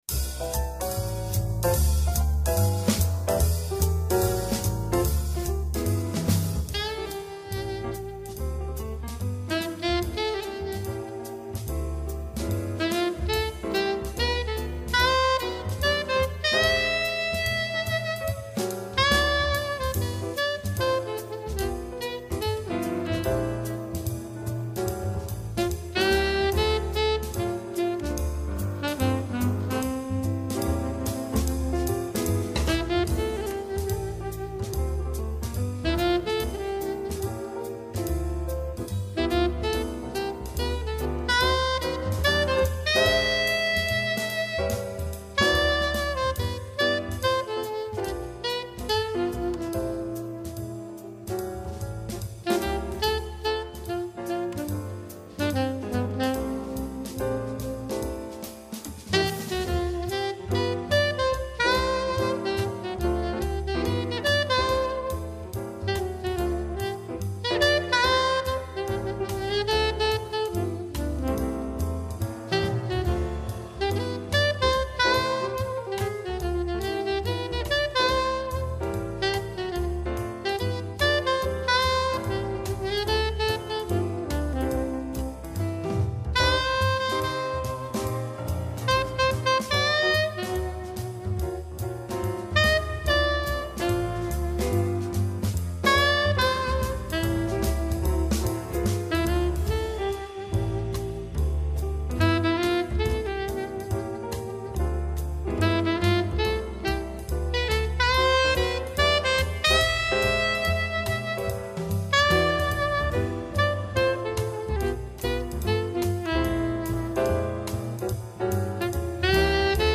TOUT LE TALENT D’UN SAXOPHONISTE
Saxophones Sopranos :